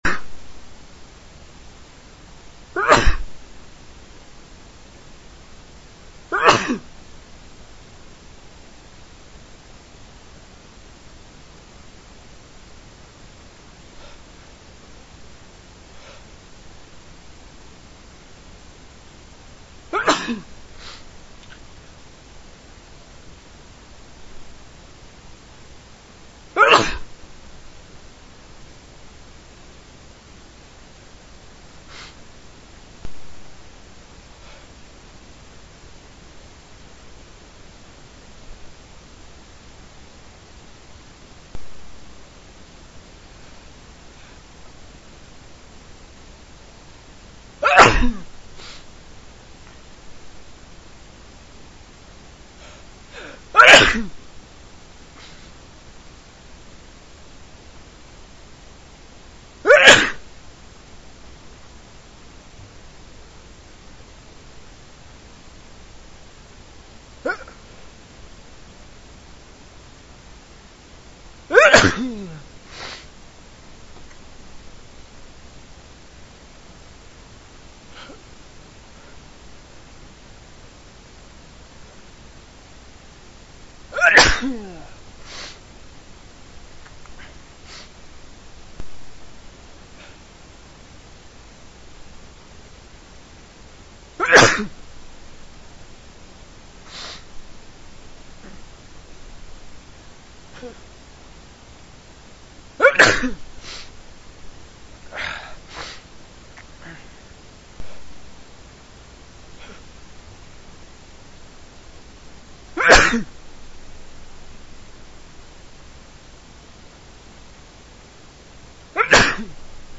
MALE WAVS